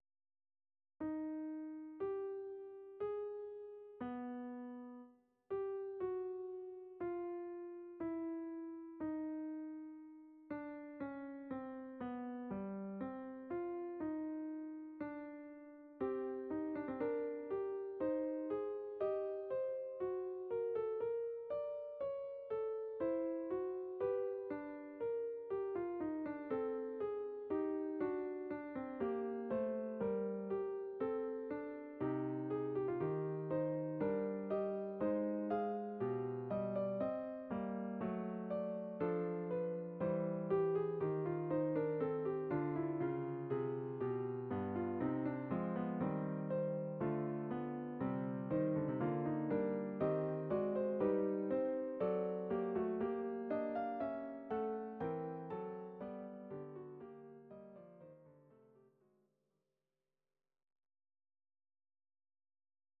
These are MP3 versions of our MIDI file catalogue.
Please note: no vocals and no karaoke included.
Your-Mix: Instrumental (2073)